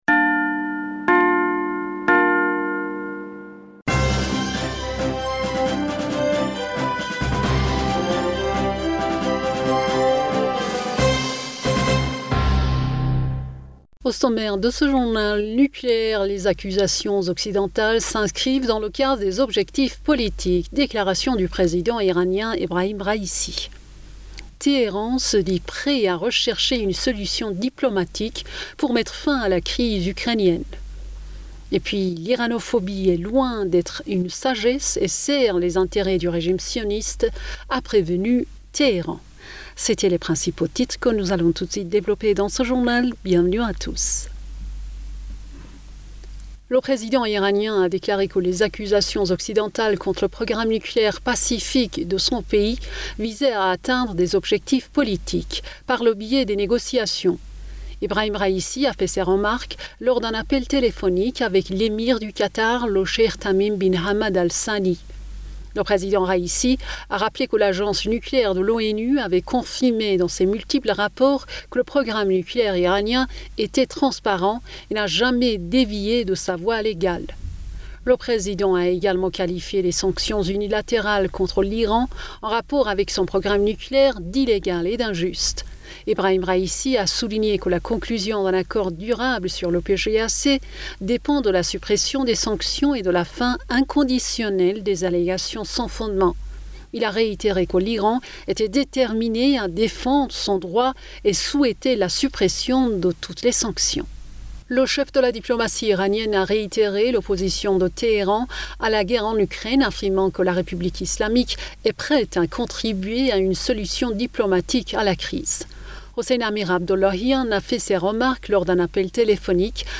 Bulletin d'information Du 01 Julliet